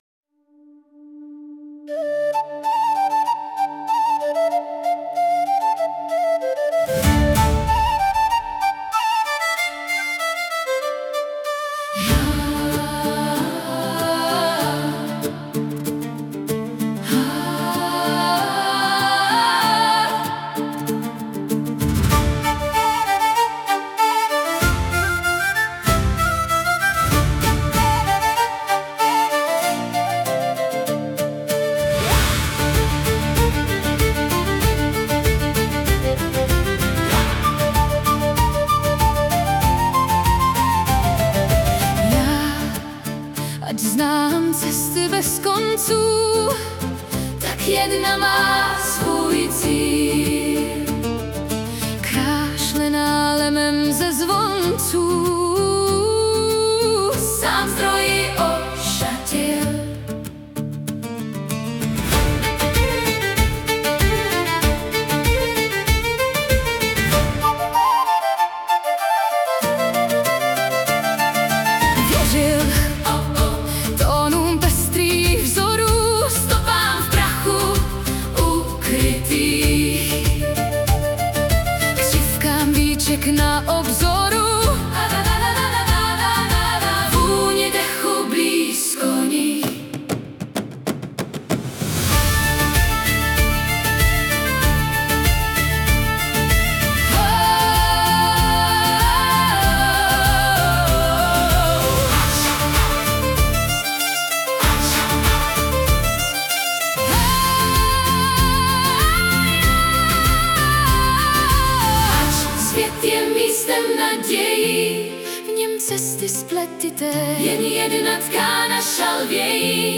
Anotace: zhudebněná verze